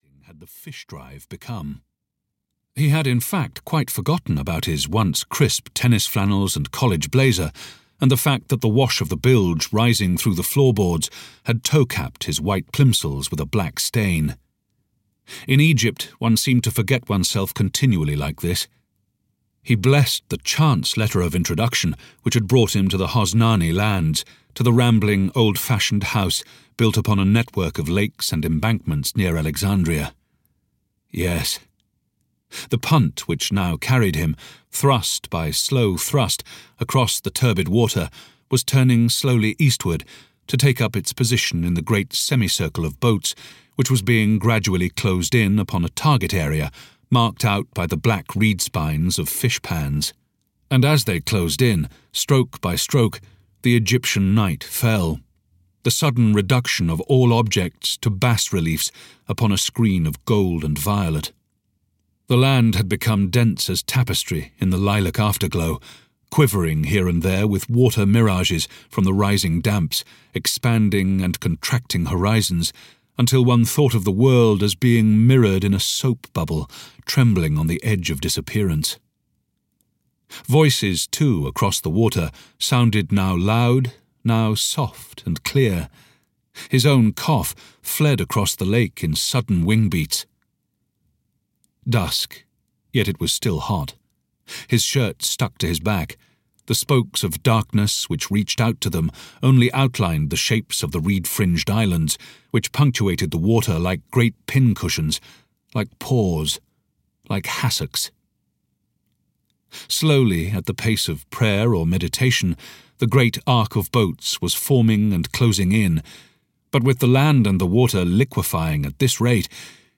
Mountolive (EN) audiokniha
Ukázka z knihy